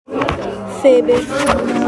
Phoebe is a beautiful name. I pronounce it as feh-BEH.